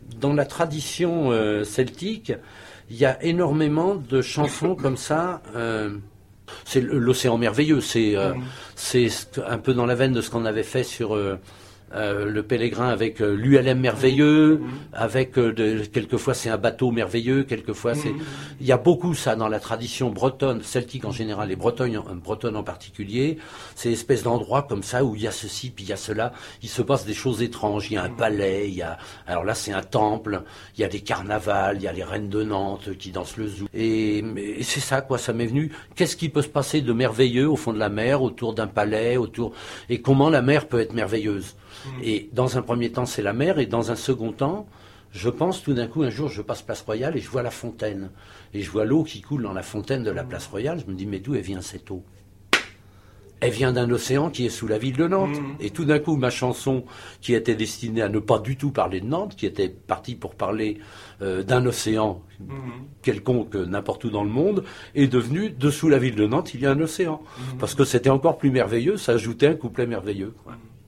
Abysses,l'INTERVIEW